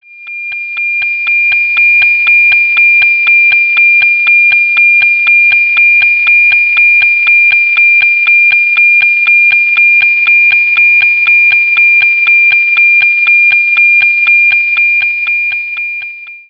sample of the APT audio received from one of the NOAA satellites that contains the visible and infrared information used to generate the images.
WXsat-sample.wav